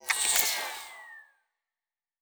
pgs/Assets/Audio/Sci-Fi Sounds/Electric/Device 6 Stop.wav at 7452e70b8c5ad2f7daae623e1a952eb18c9caab4
Device 6 Stop.wav